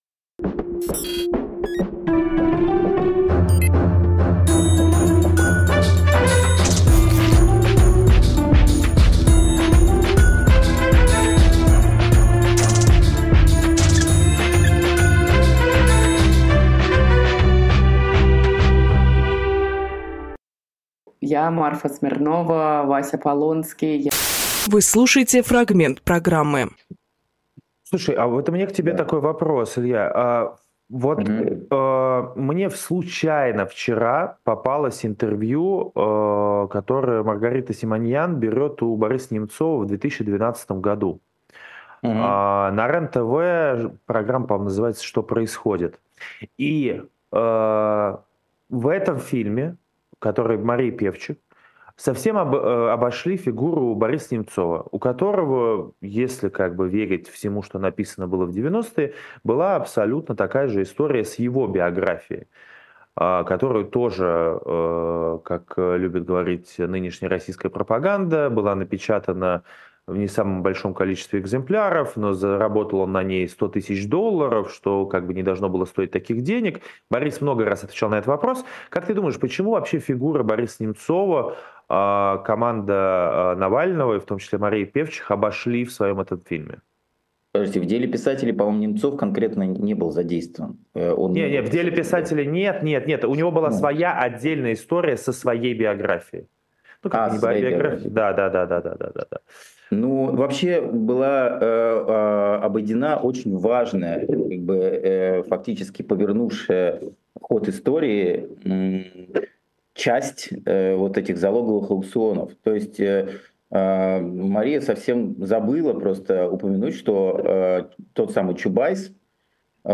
Фрагмент эфира от 04.05.24